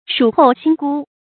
曙后星孤 注音： ㄕㄨˇ ㄏㄡˋ ㄒㄧㄥ ㄍㄨ 讀音讀法： 意思解釋： 曙：破曉時光。